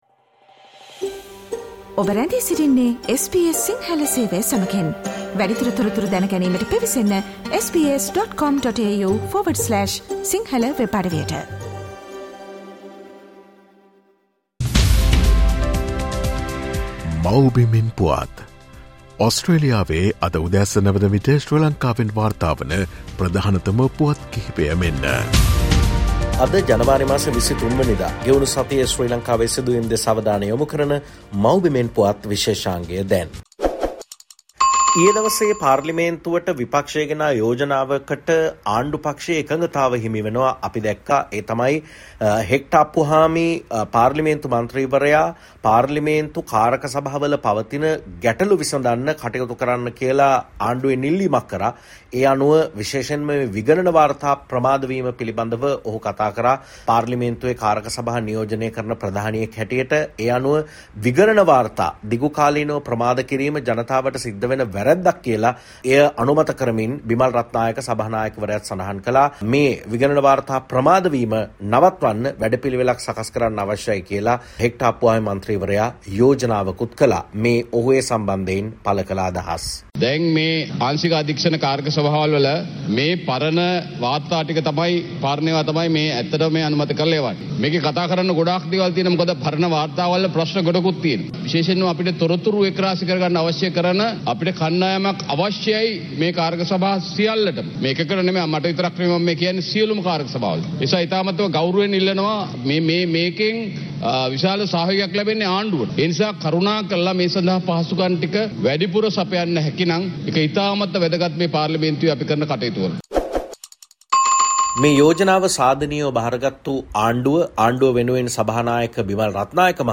මේ සතියේ ශ්‍රී ලංකාවෙන් වාර්තා වූ උණුසුම් හා වැදගත් පුවත් සම්පිණ්ඩනය.